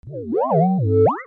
Déplacement 4.mp3